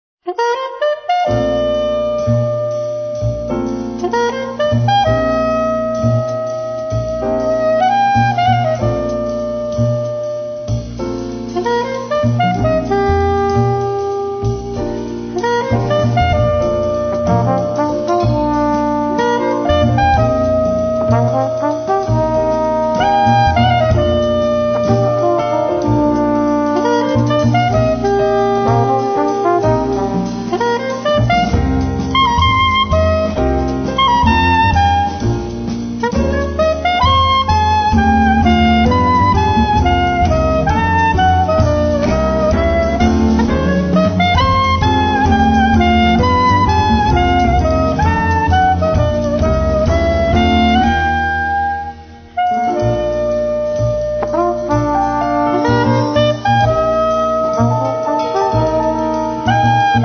Sassofono tenore e soprano
tromba e flicorno
Pianoforte
Contrabbasso
Batteria
una melodia nostalgica